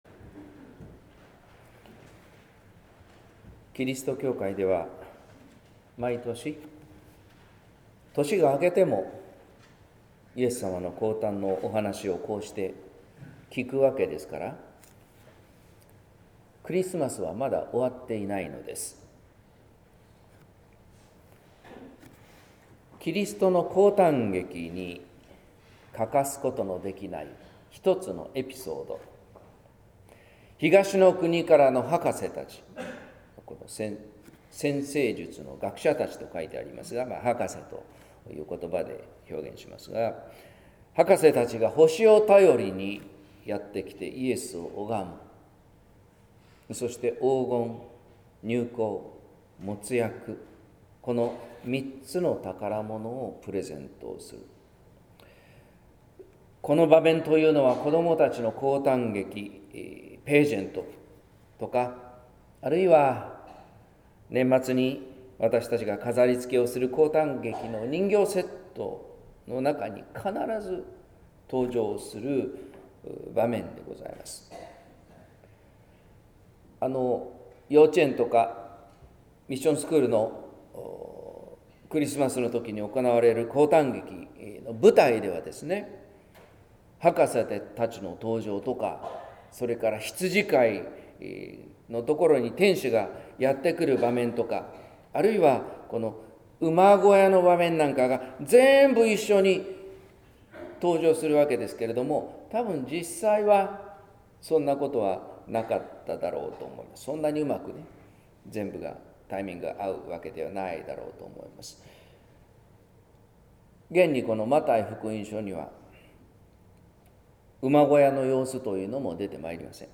説教「初めからグローバル」（音声版） | 日本福音ルーテル市ヶ谷教会